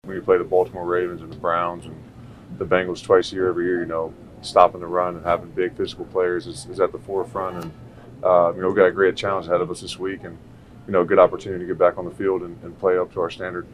In the locker room yesterday, the players were at a loss to explain how bad they were against the Bills.